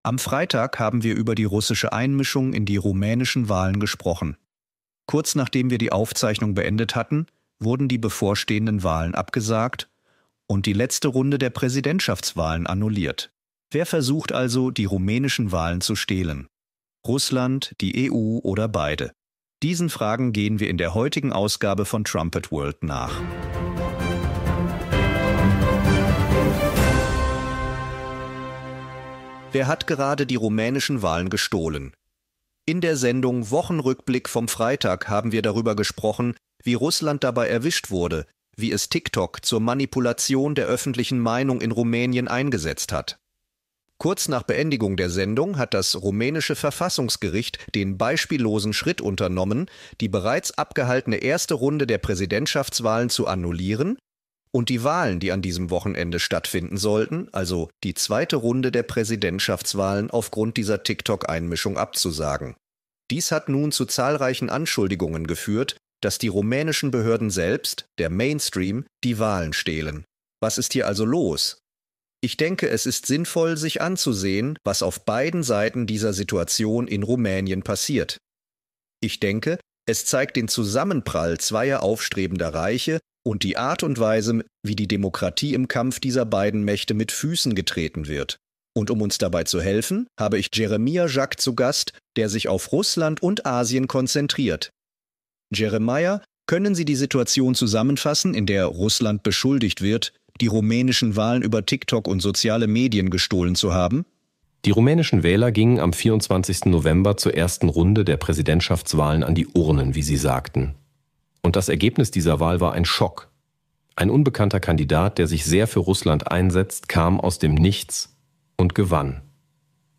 Nehmen Sie an der Diskussion teil, wenn die Mitarbeiter der Posaune die jüngsten Nachrichten mit der biblischen Prophetie vergleichen.